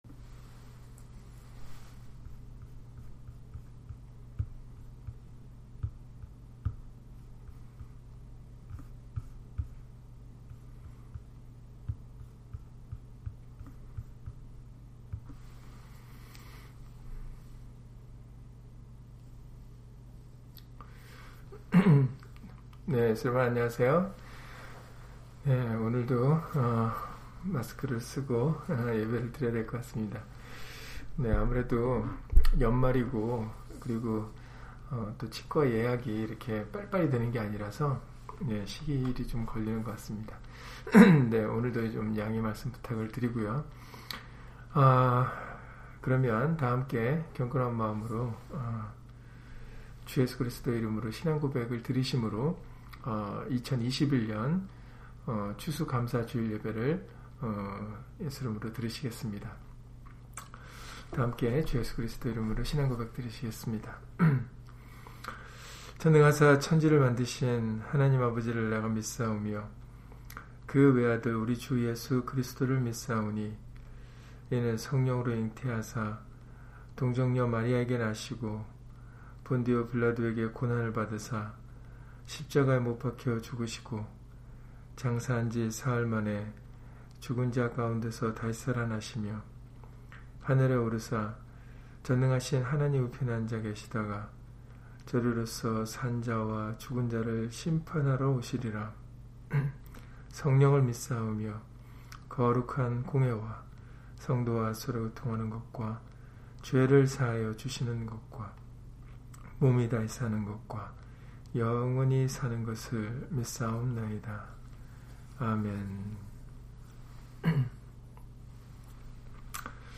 시편 107편 21-22절 [추수감사 주일] - 주일/수요예배 설교 - 주 예수 그리스도 이름 예배당